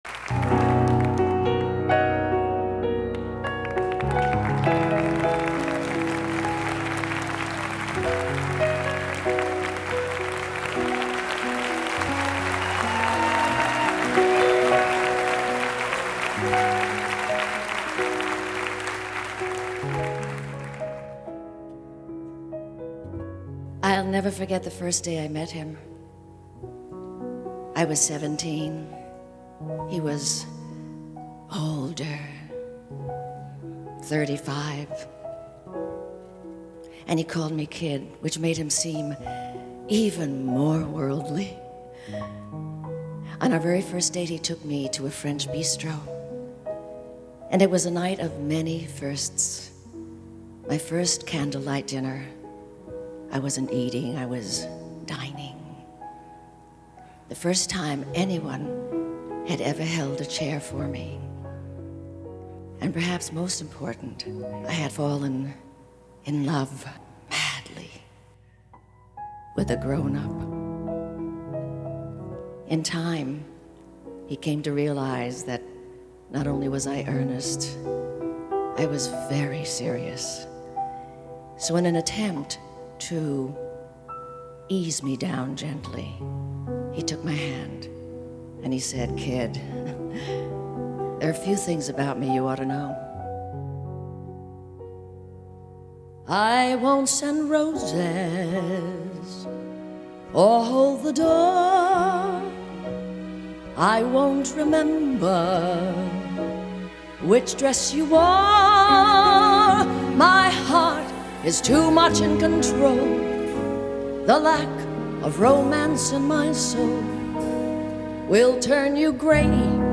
Musical   Composer